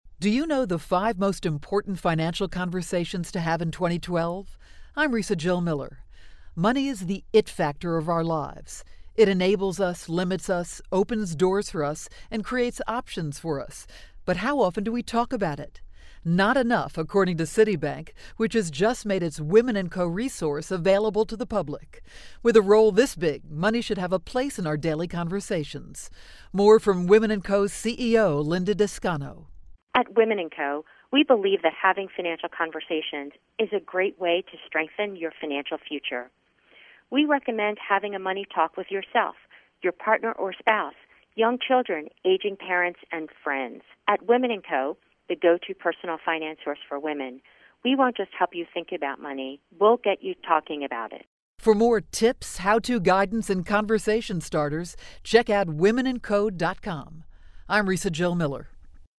February 9, 2012Posted in: Audio News Release